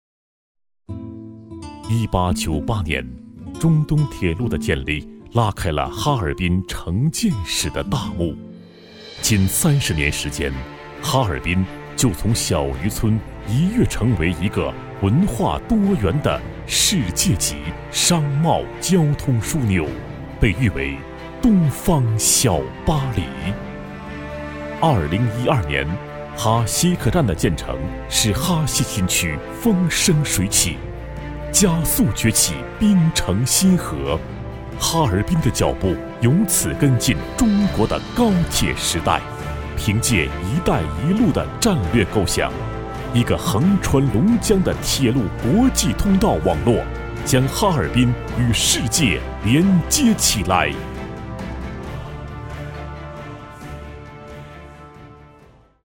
男22 - 哈尔滨（浑厚大气） 浑厚 男22央视大气
男22 哈尔滨（浑厚大气）.mp3